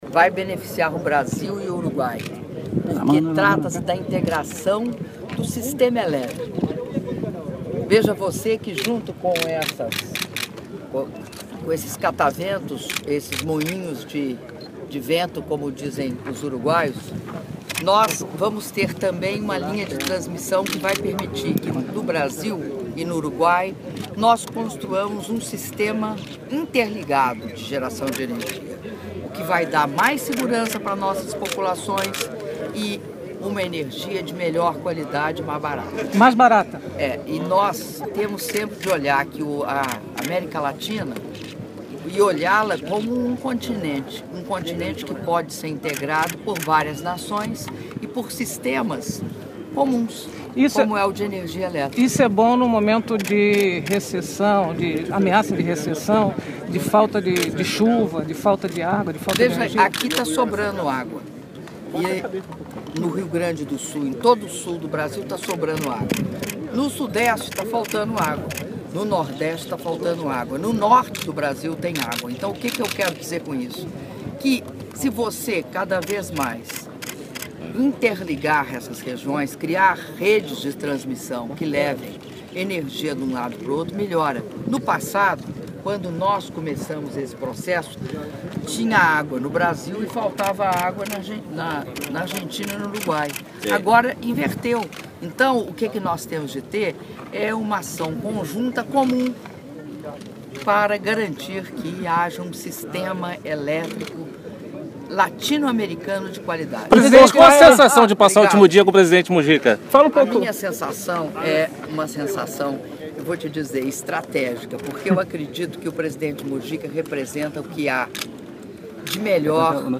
Áudio da entrevista concedida pela Presidenta da República, Dilma Rousseff, na chegada a Colônia-Uruguai (5min37s)